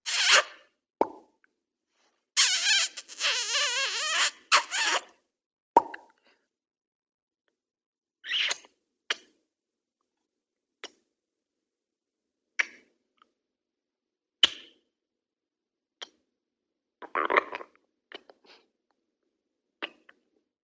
Звуки поцелуя
Мультипликационные вариации серия